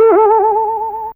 BoingHi.wav